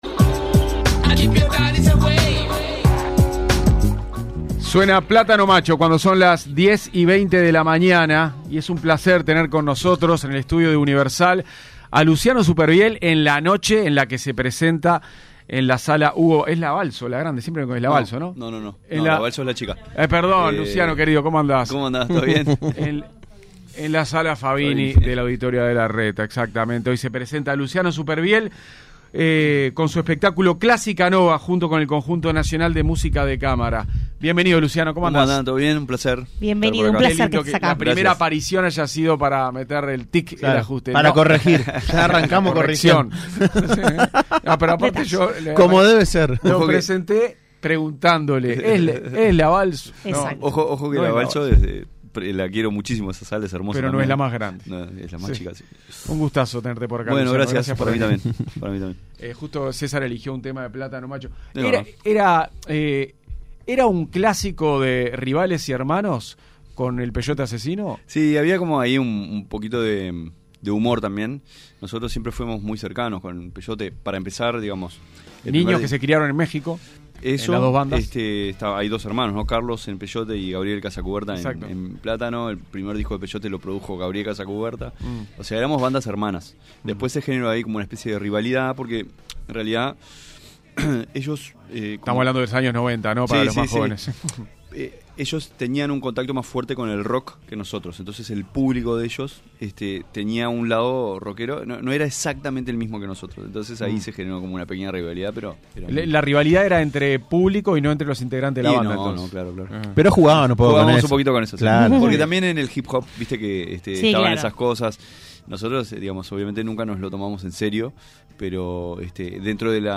Entrevista-Luciano-Supervielle.mp3